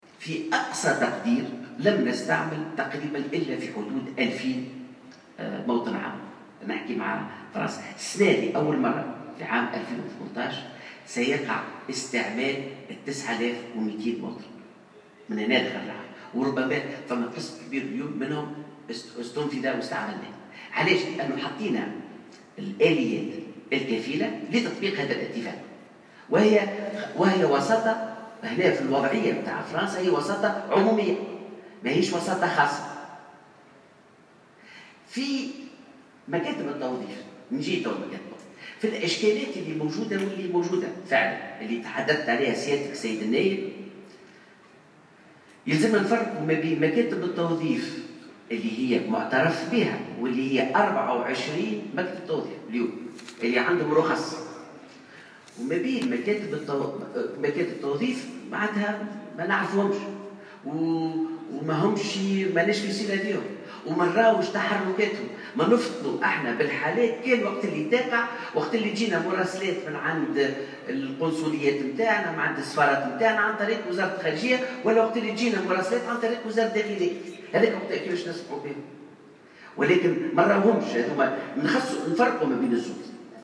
وأوضح الوزير خلال جلسة استماع بمجلس نواب الشعب،